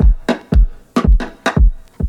• 115 Bpm High Quality Breakbeat Sample F# Key.wav
Free breakbeat sample - kick tuned to the F# note. Loudest frequency: 285Hz
115-bpm-high-quality-breakbeat-sample-f-sharp-key-65t.wav